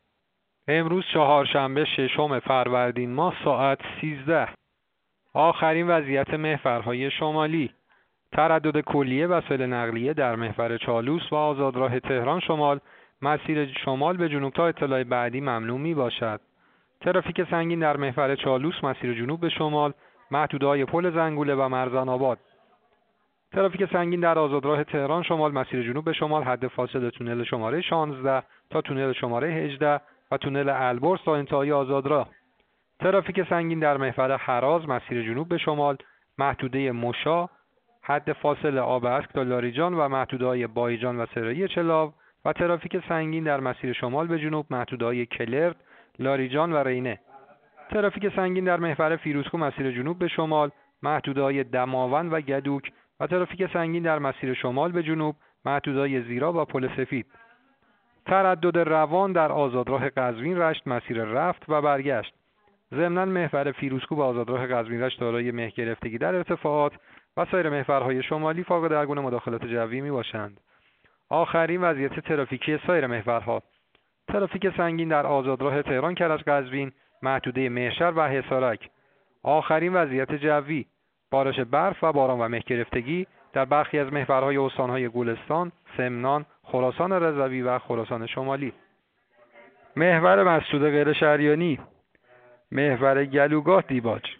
گزارش رادیو اینترنتی از آخرین وضعیت ترافیکی جاده‌ها ساعت ۱۳ ششم فروردین؛